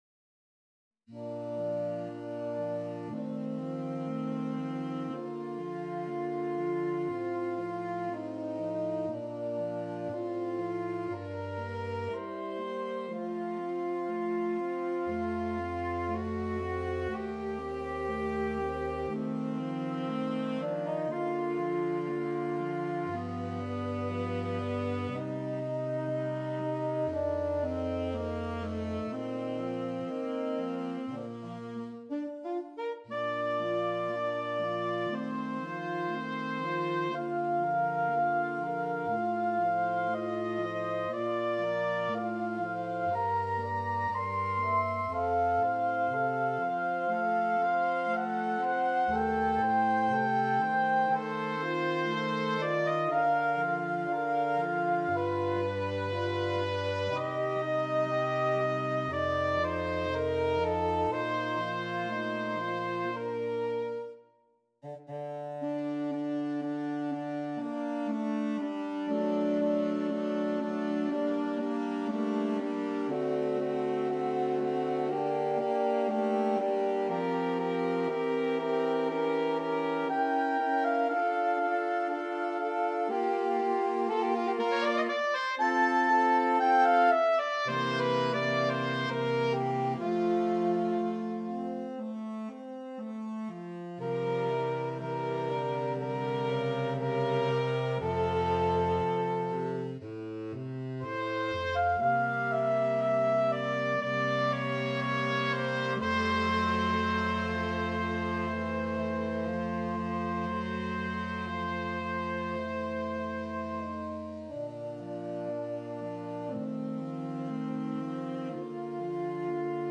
Saxophone Quartet (SATB)